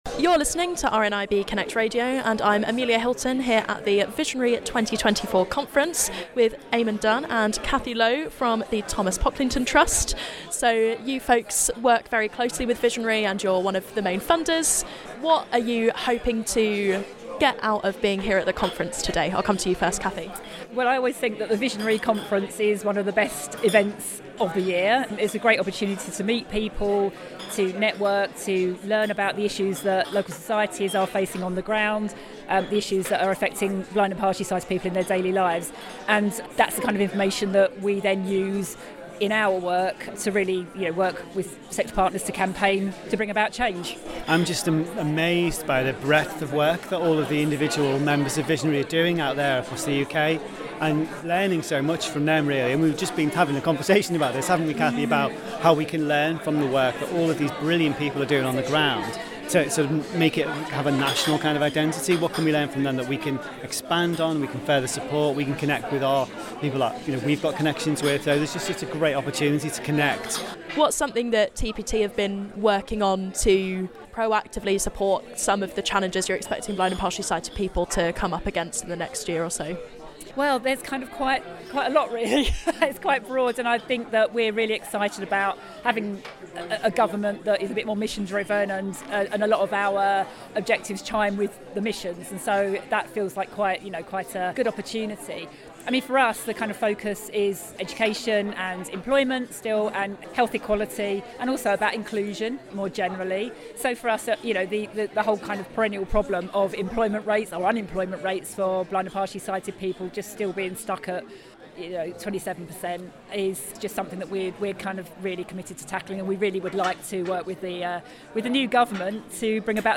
Back to the conference floor